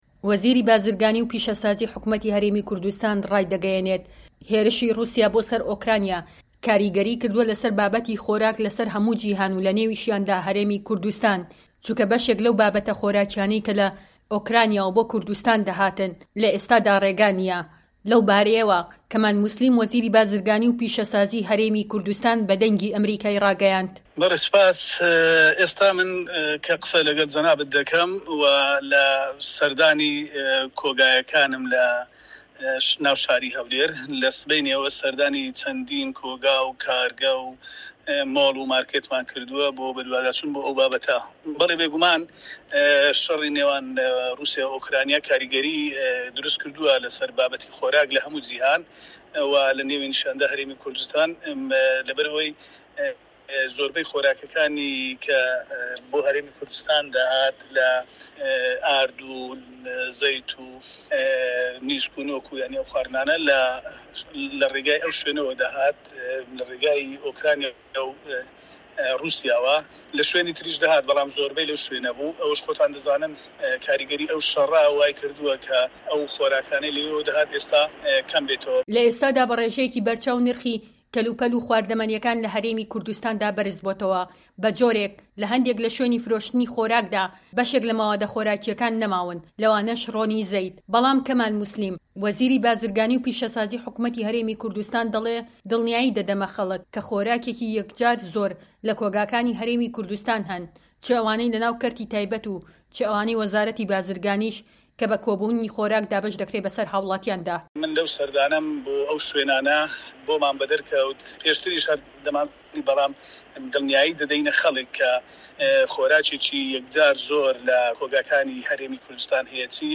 وتووێژ لەگەڵ وەزیری بازرگانی و پیشەسازی هەرێمی کوردستان